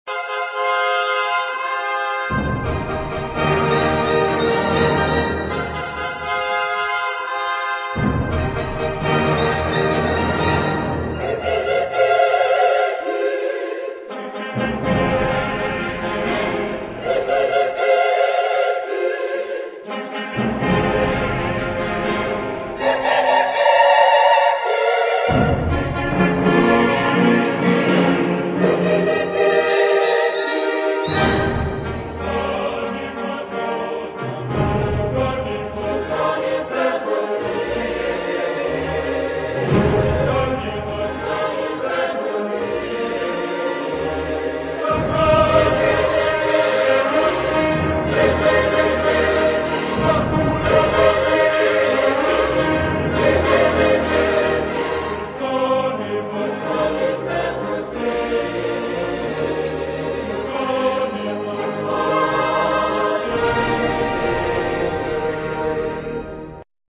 Tonalité : la bémol majeur